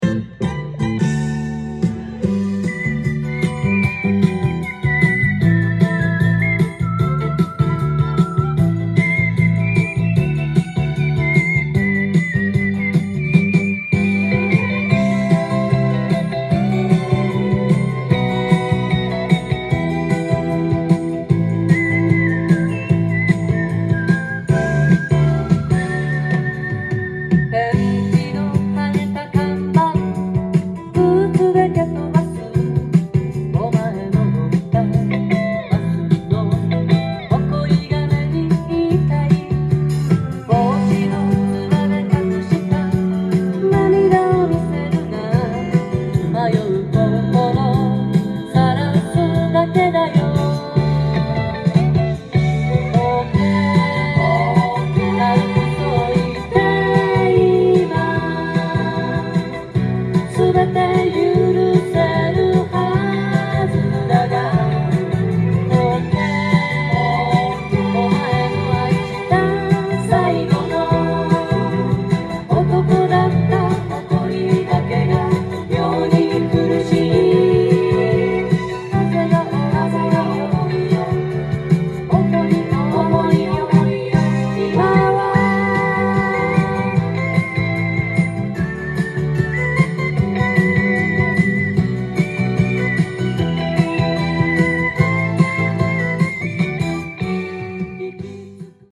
ジャンル：FOLKSONG
店頭で録音した音源の為、多少の外部音や音質の悪さはございますが、サンプルとしてご視聴ください。